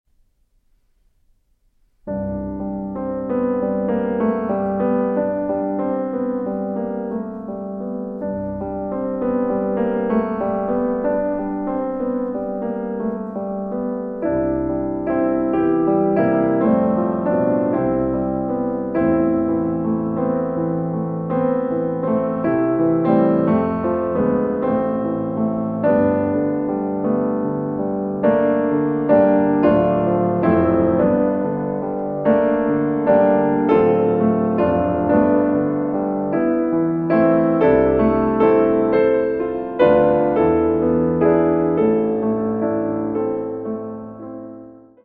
Poco adagio notturnale (4:21)